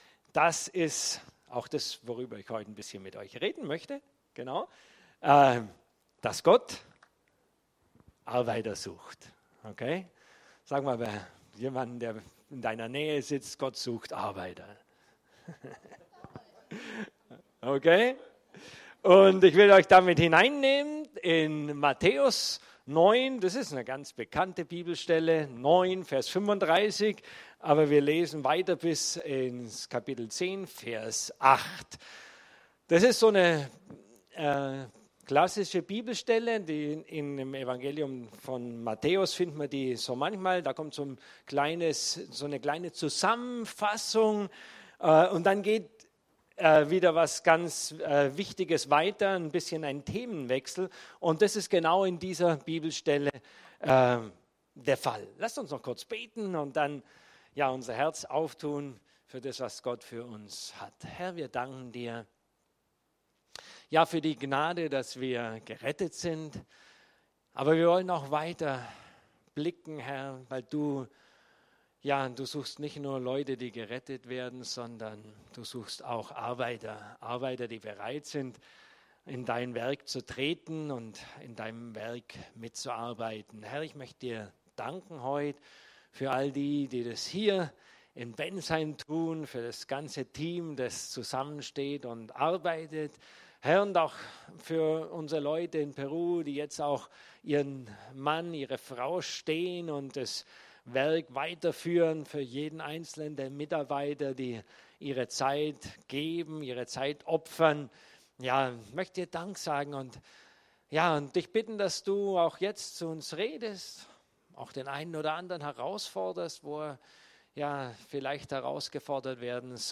Gott sucht Arbeiter ~ CZB Bensheim Predigten Podcast